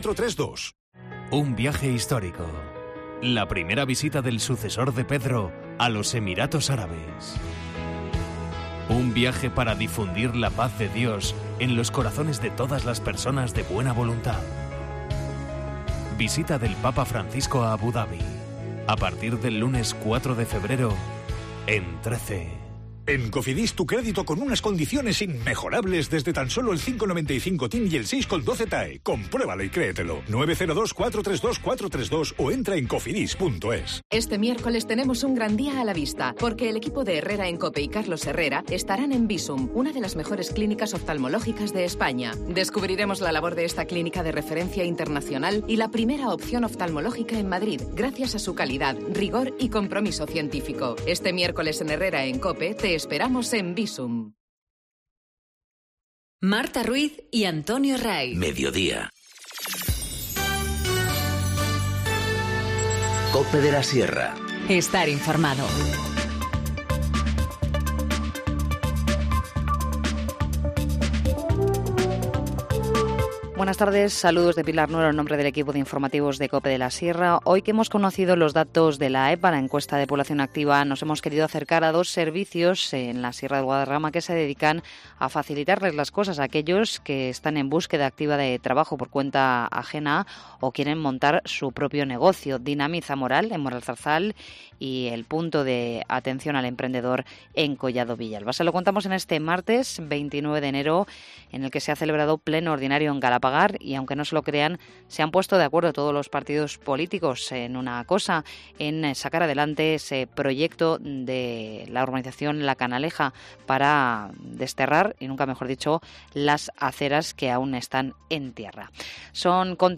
Informativo Mediodía 29 enero- 14:20h